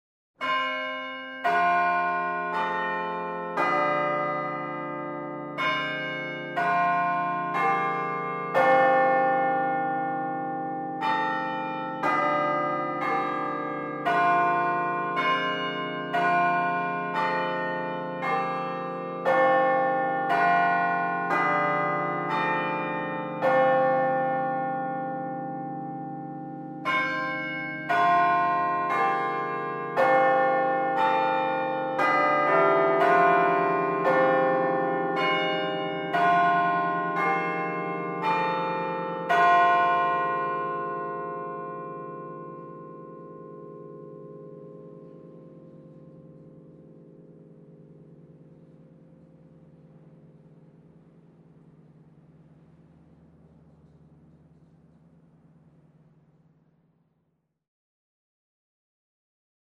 Звуки звонка в дверь
Таинственный стук в дверь замка